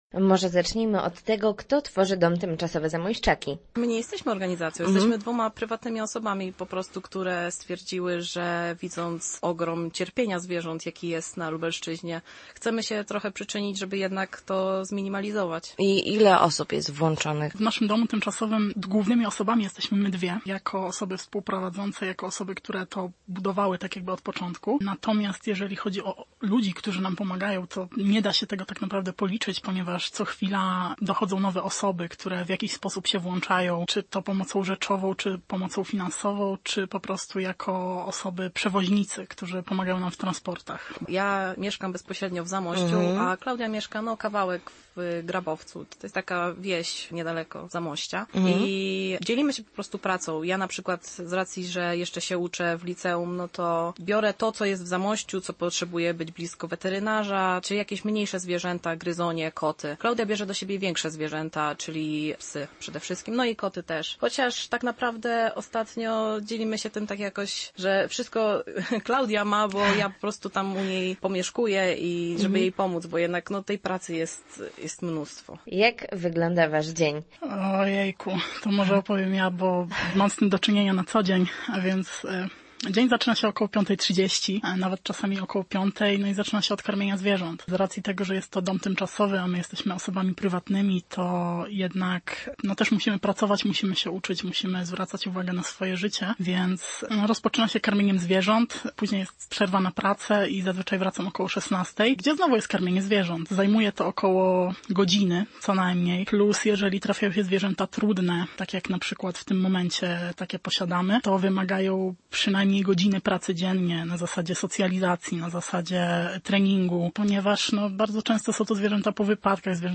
O swojej pracy opowiedzą nam dwie kobiety, które tworzą bezpieczne miejsce dla zwierząt dotkniętych przez los.